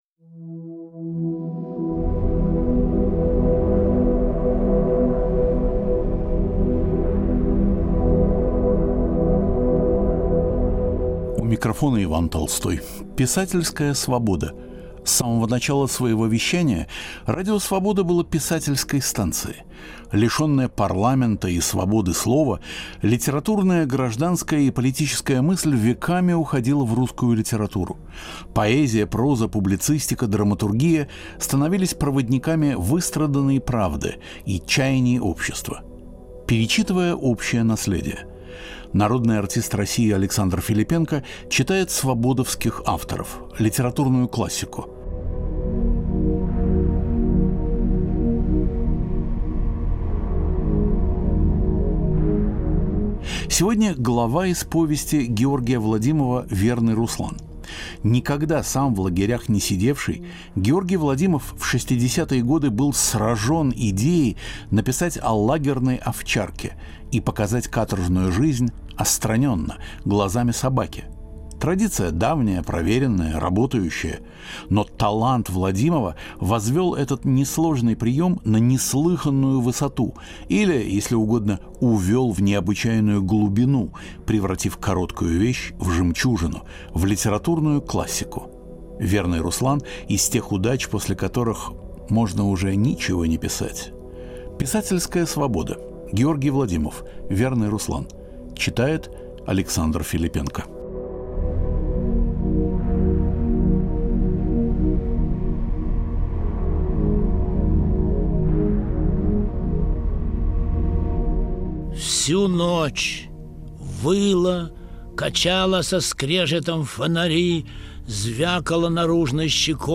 Александр Филиппенко читает главу из повести Георгия Владимова "Верный Руслан"